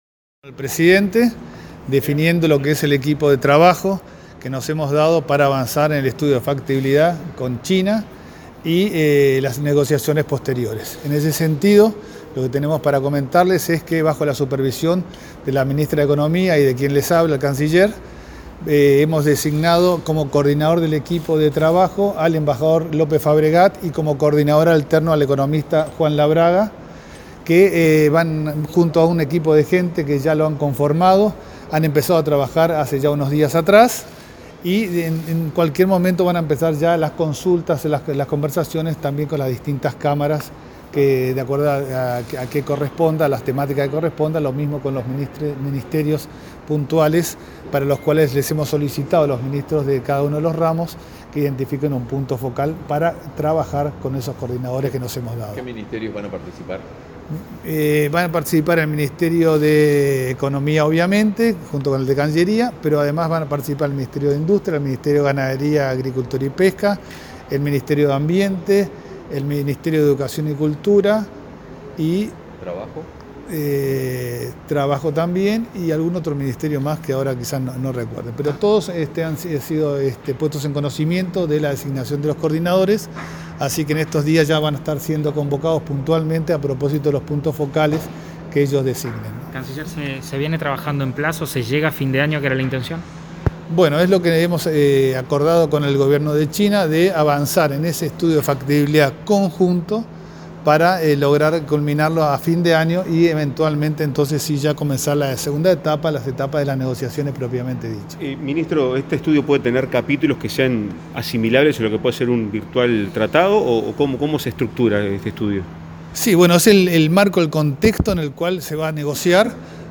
Declaraciones del canciller Francisco Bustillo a la prensa
El Ministerio de Relaciones Exteriores y el Ministerio de Economía y Finanzas anunciaron que ya comenzó su tarea el equipo negociador del estudio de factibilidad del tratado de libre comercio con China, bajo la supervisión del canciller Francisco Bustillo y la ministra Azucena Arbeleche. Bustillo, tras participar en los acuerdos ministeriales con el presidente Luis Lacalle Pou, este martes 28, efectuó declaraciones a la prensa.